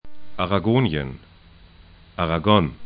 Aragonien ara'go:nĭən Aragón ara'gɔn